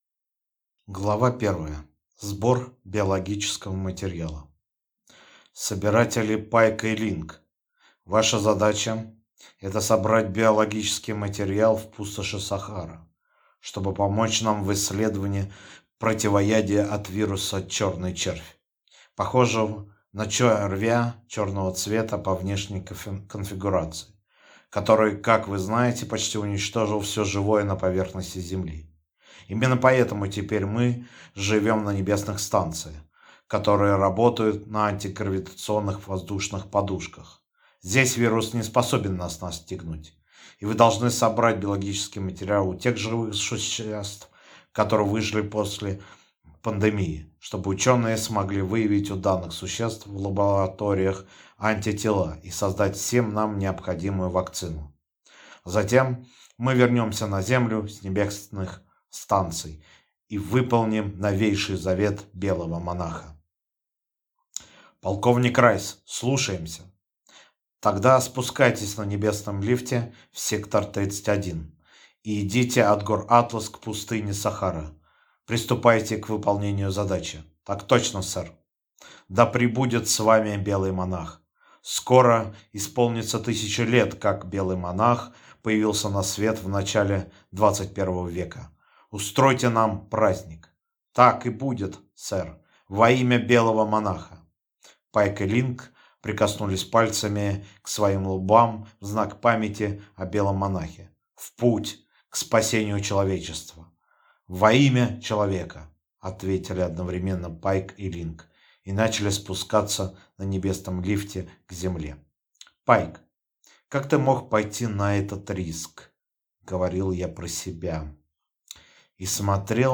Аудиокнига Небесные станции. Путь к спасению | Библиотека аудиокниг